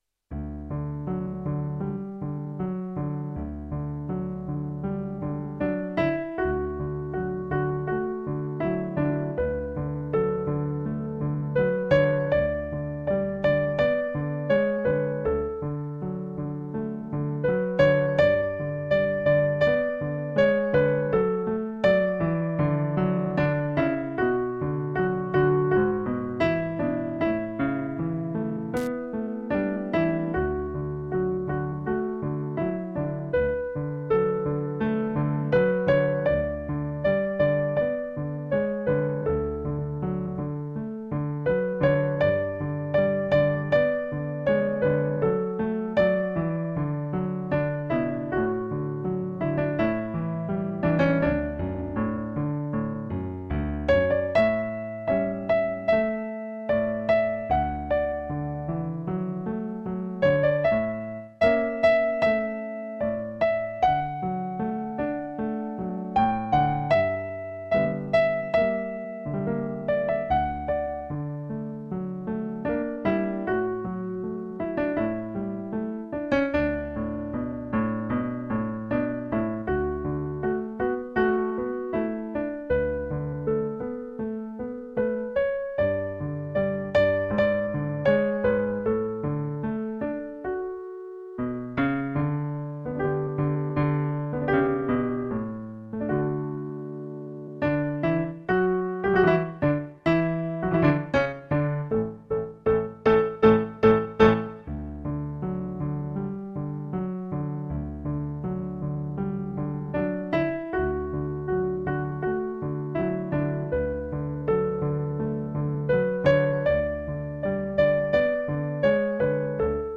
Partitura para piano / Piano score (pdf)